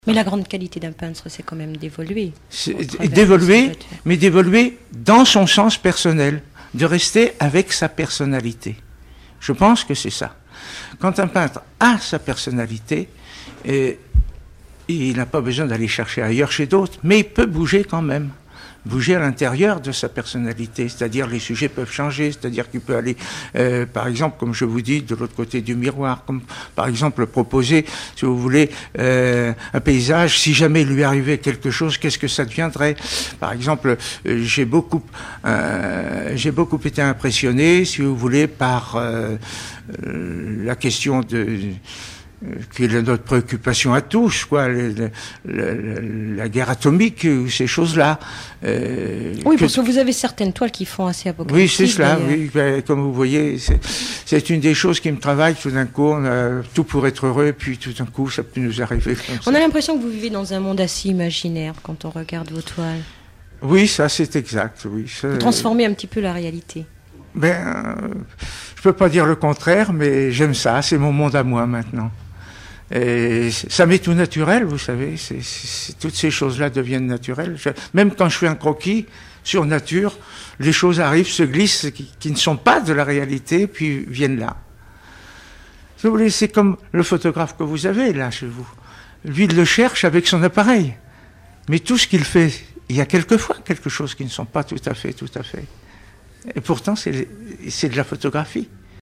Enquête Alouette FM numérisation d'émissions par EthnoDoc
Catégorie Témoignage